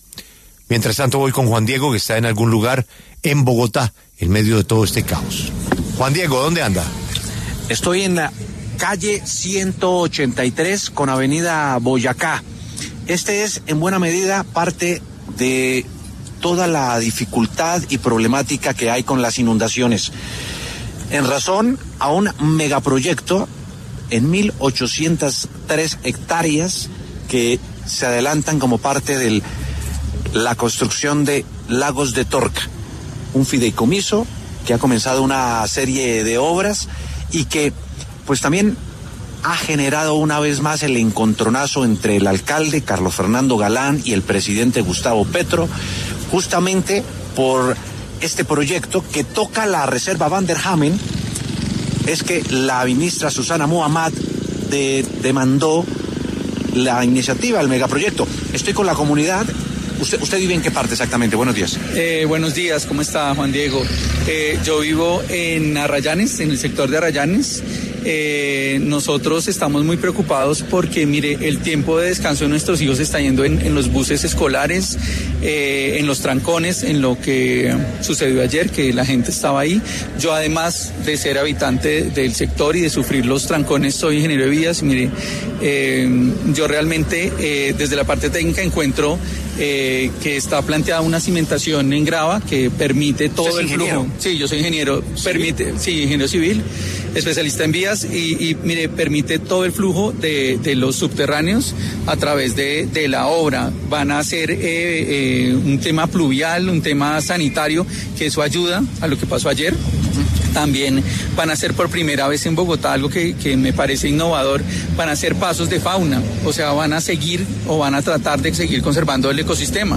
El concejal de Bogotá, Rolando González, conversó con La W sobre el proyecto en la 183 con Av. Boyacá que ha generado controversias políticas entre la Presidencia y la Alcaldía.